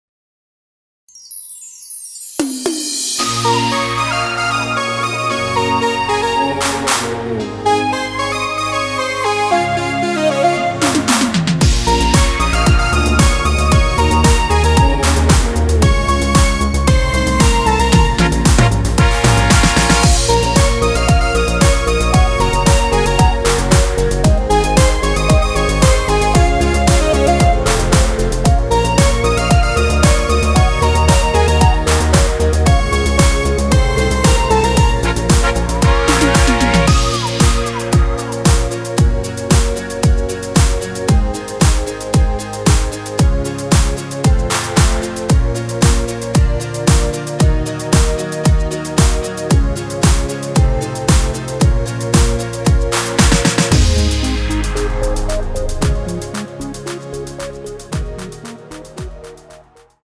원키에서(-4)내린  MR입니다.
Bbm
앞부분30초, 뒷부분30초씩 편집해서 올려 드리고 있습니다.
중간에 음이 끈어지고 다시 나오는 이유는